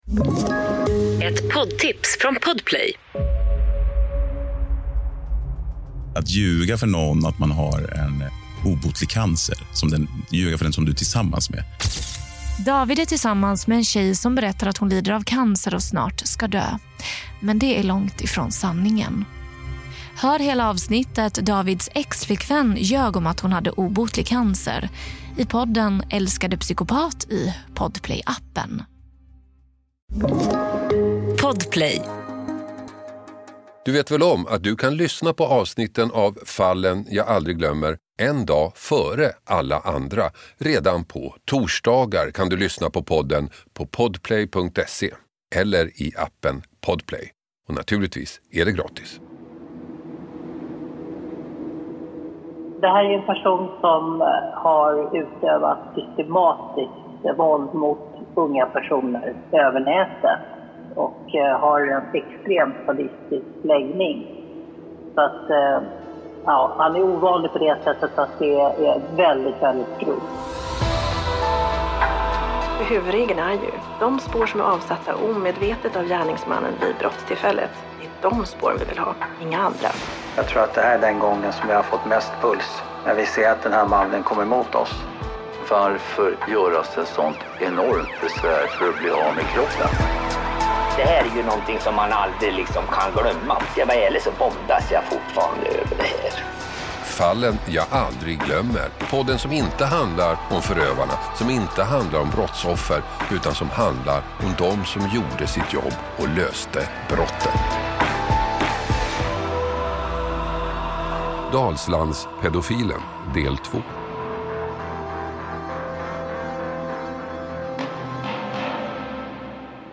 Hasse Aro intervjuar